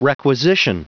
Prononciation du mot requisition en anglais (fichier audio)
Prononciation du mot : requisition